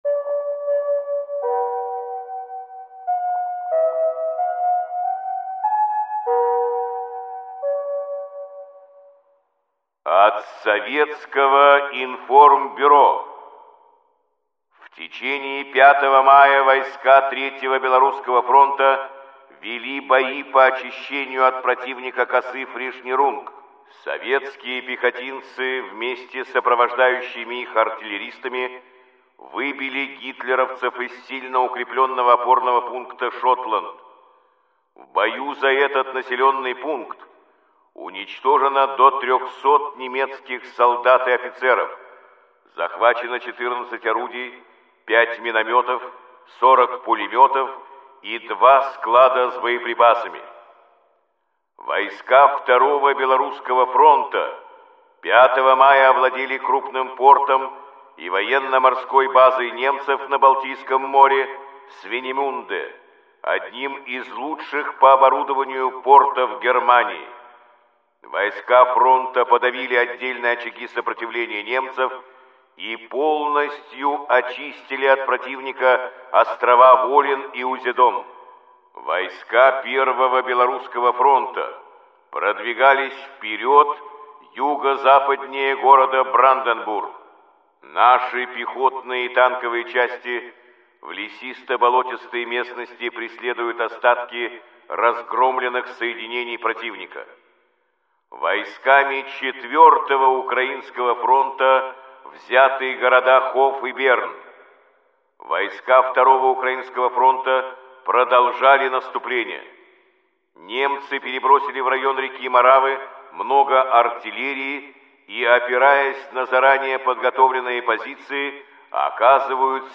Каждый день – это хронология самых важных событий и подвигов героев Великой Отечественной войны, рассказанная в сообщениях СОВИНФОРМБЮРО голосом Юрия Борисовича Левитана.